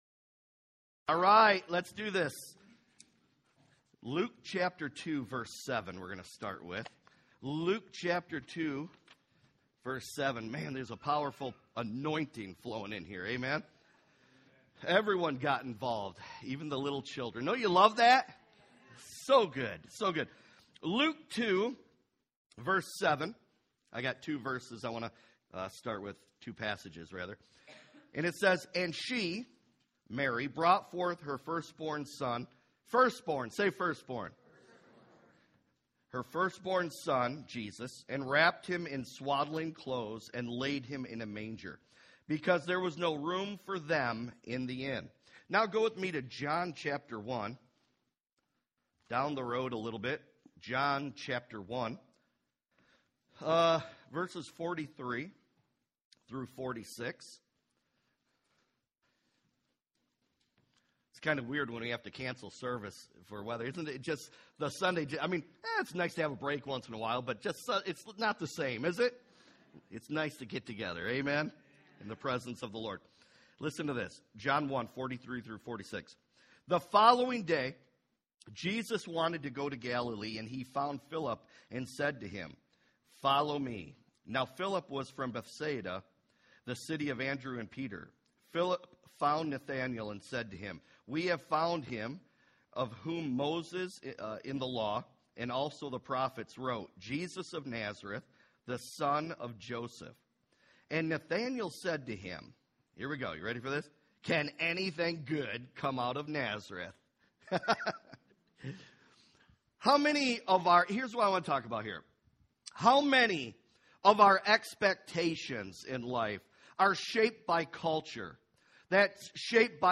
unexpected-packages-sermon.mp3